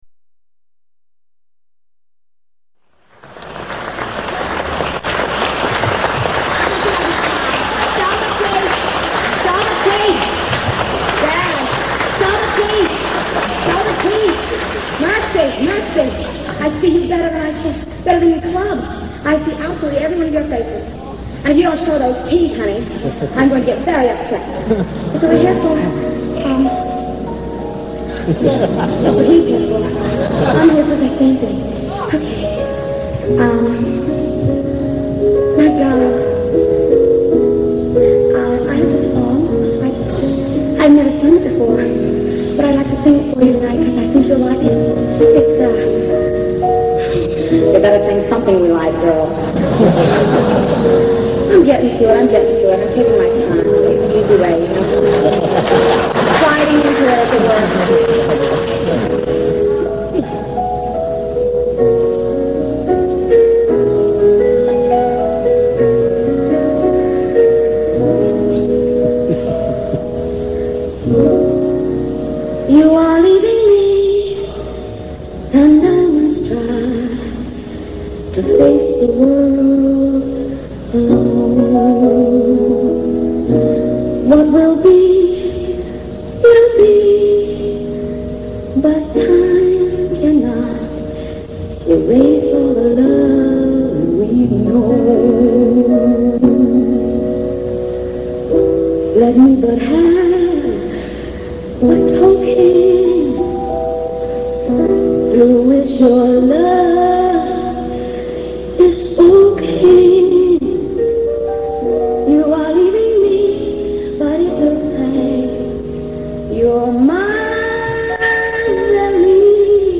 Carnegie Hall, 1972